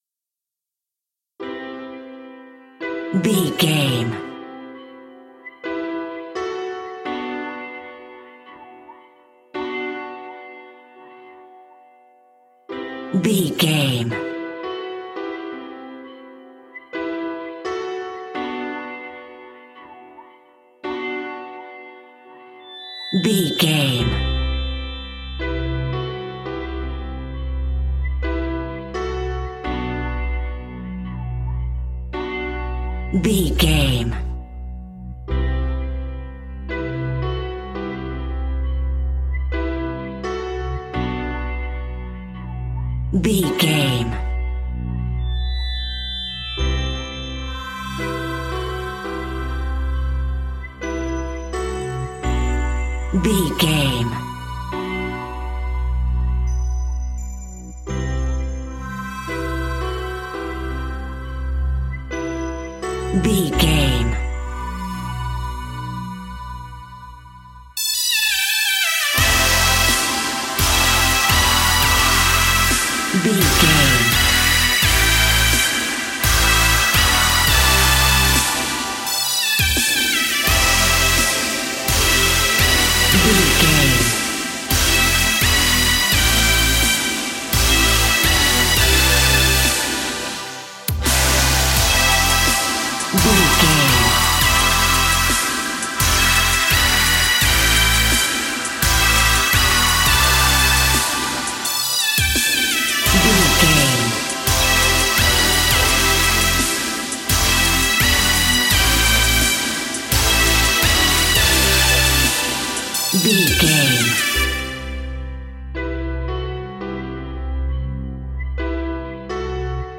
Aeolian/Minor
aggressive
powerful
dark
funky
groovy
uplifting
driving
energetic
piano
electric piano
synthesiser
drum machine
electronic
techno
trance
synth leads
synth bass